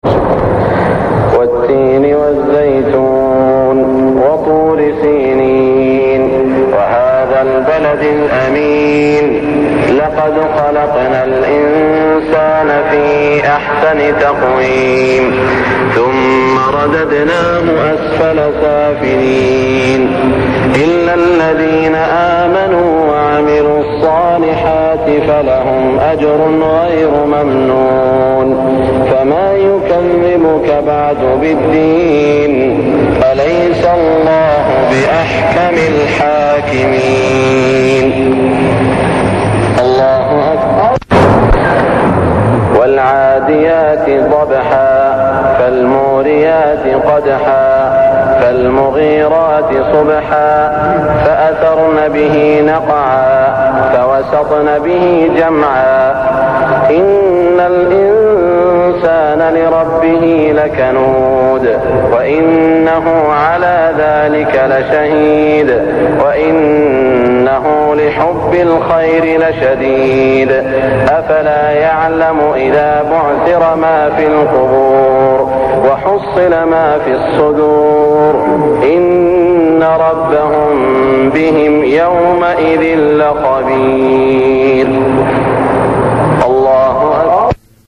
صلاة المغرب 1416هـ سورتي التين و العاديات > 1416 🕋 > الفروض - تلاوات الحرمين